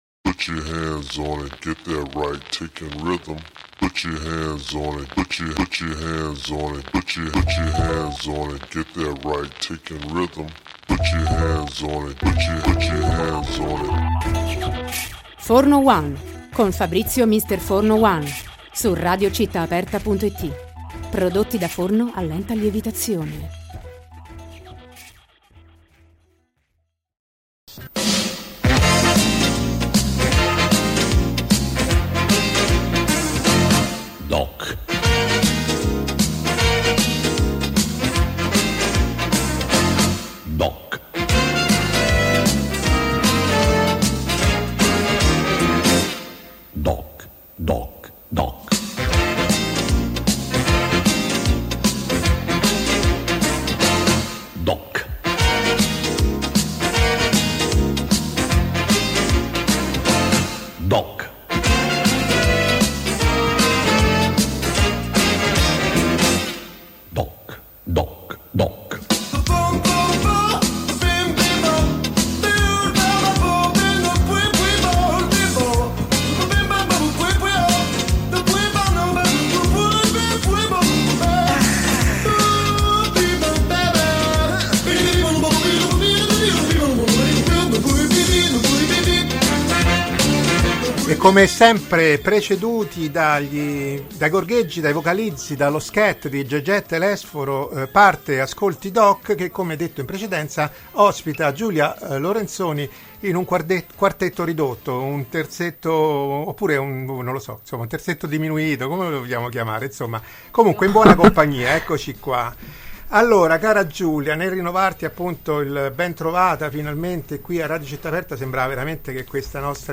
Ascolti DOC: intervista